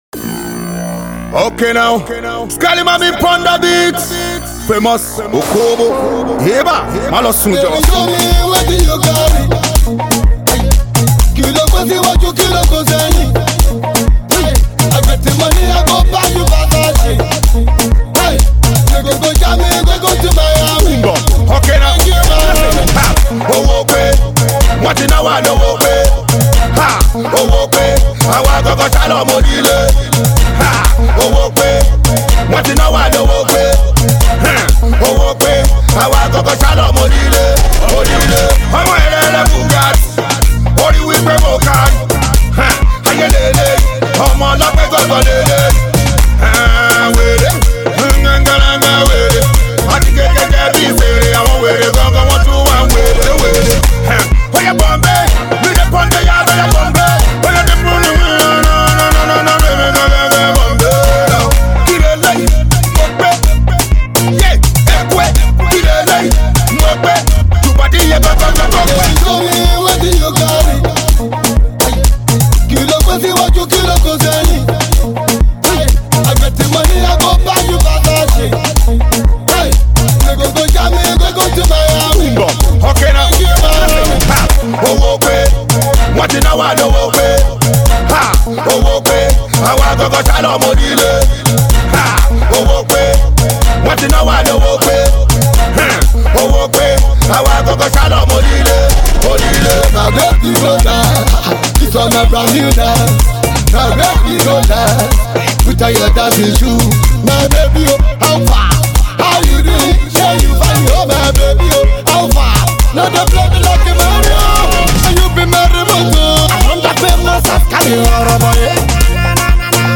AudioPop
Street Banger